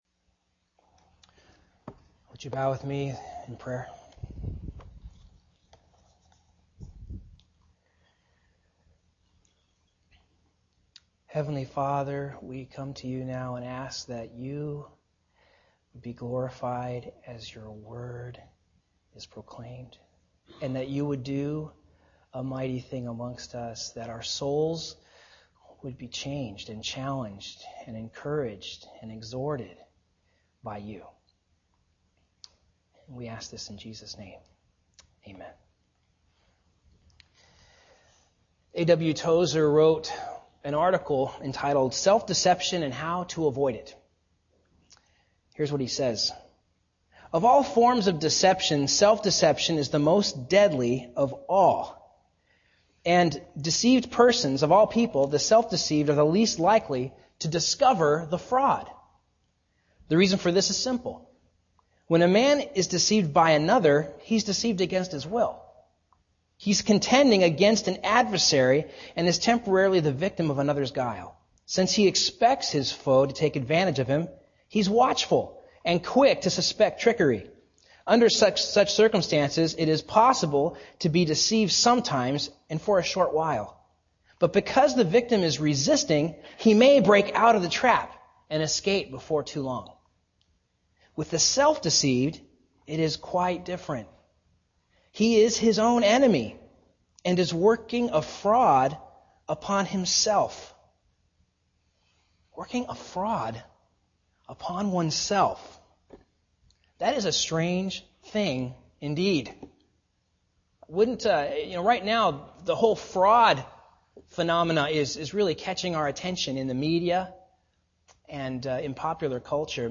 9:25am Primary Passage: Romans 1:18-32 Preacher